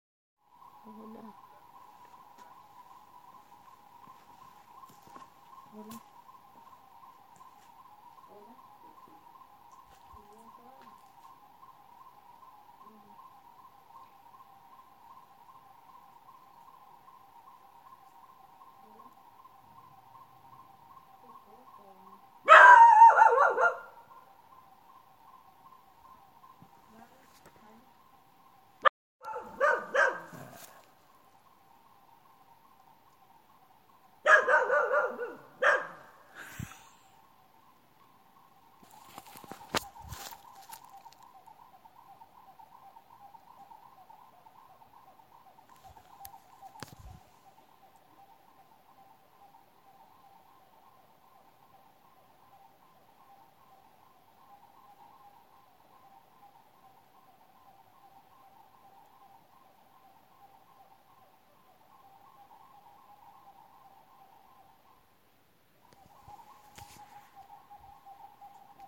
Urú (Odontophorus capueira)
Nombre en inglés: Spot-winged Wood Quail
Localización detallada: Sendero cerro santa ana (el Monte de los Abuelos)
Condición: Silvestre
Certeza: Vocalización Grabada